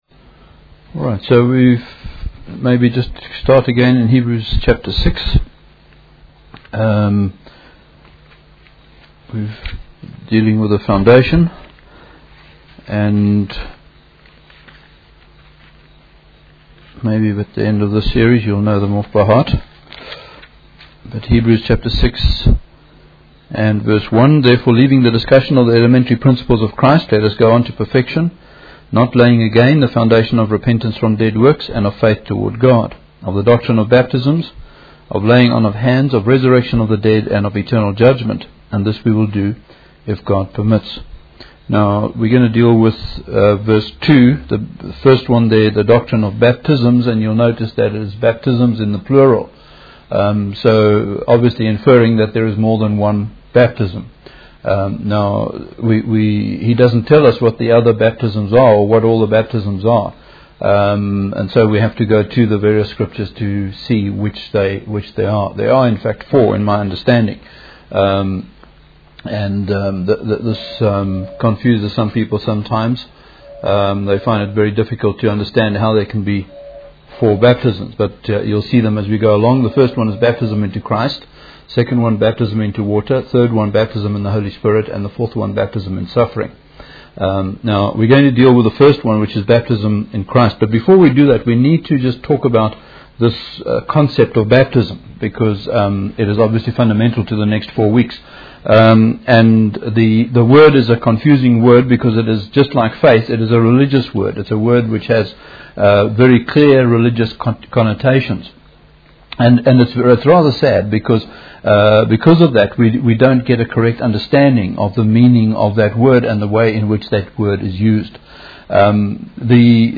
In this sermon, the speaker focuses on the concept of being new people in Christ. He emphasizes that as Christians, we have died to sin and it no longer has a hold over us.